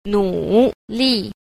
3. 努力 – nǔlì – nỗ lực